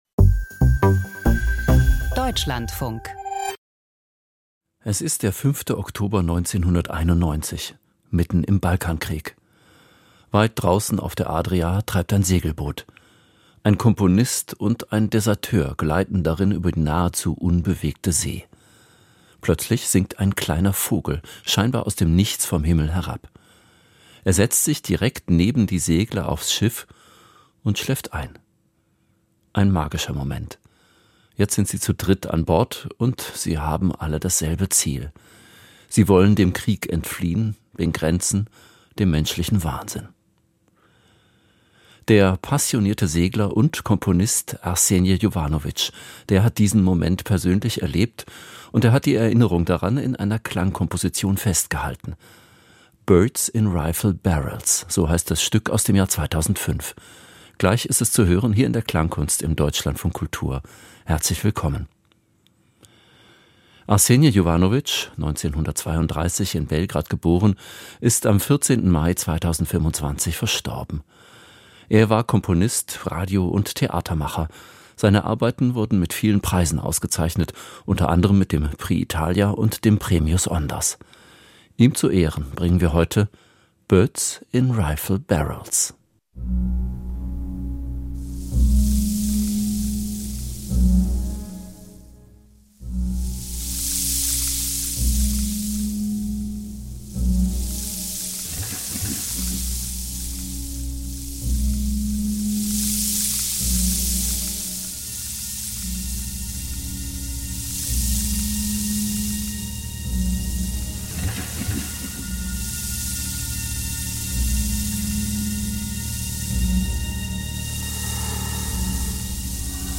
Klangkunst: Segeln für den Frieden - Birds in Rifle Barrels